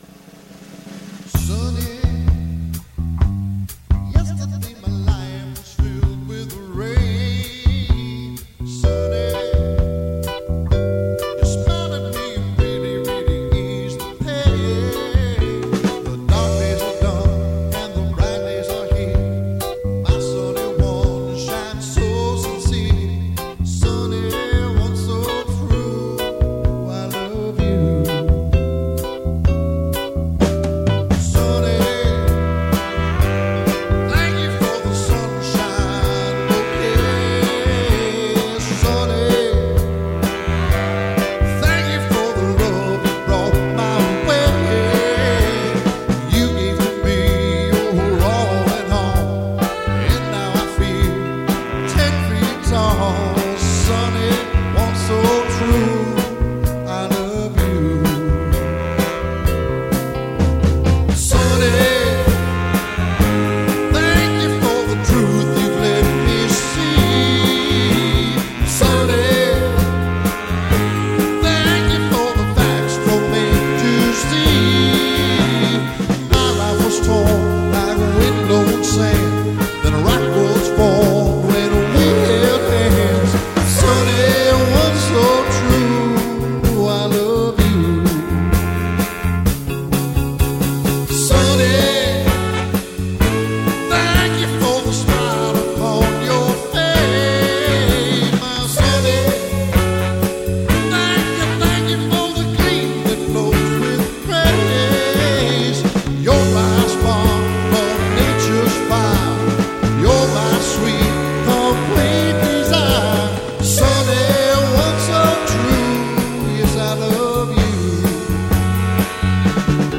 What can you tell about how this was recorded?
LIVE RECORDINGS DURING ACTUAL SHOW (full songs):